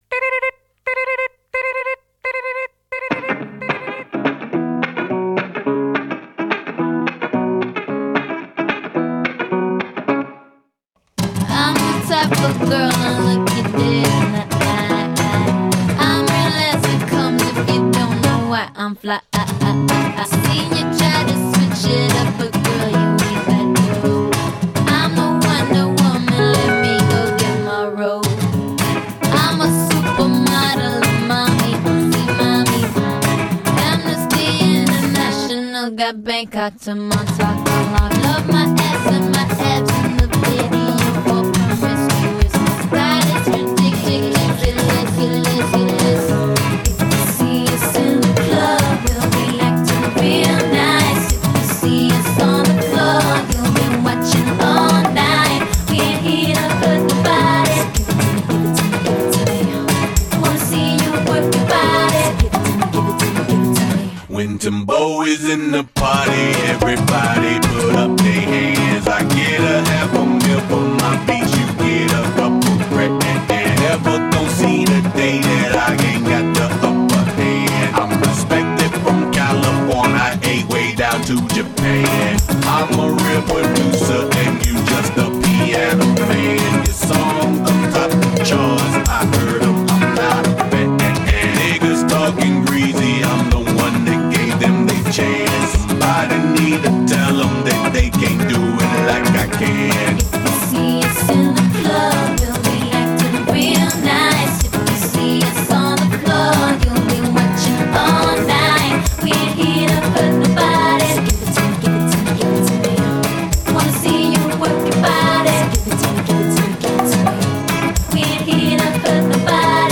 Genre: Hip-Hop